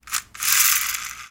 标签： 发电机 破败 关断 发动机
声道立体声